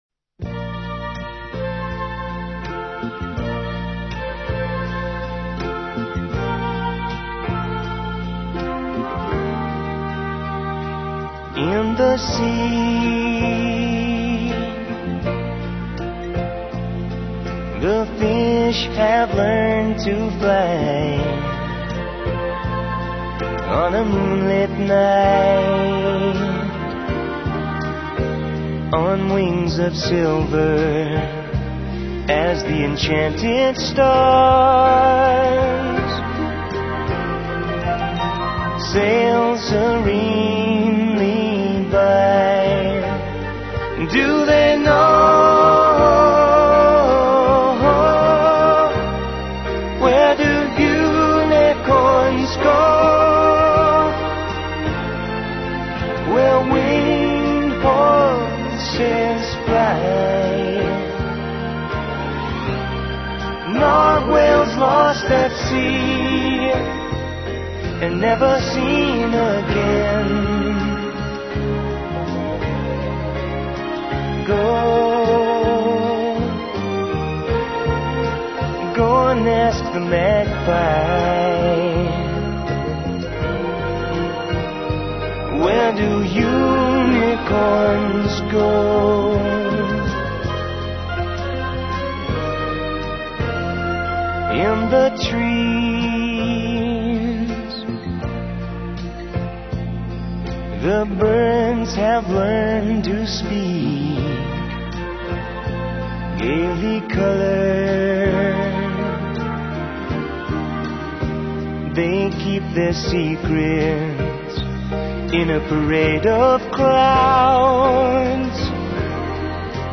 Live sound (mp3 file) Click here to download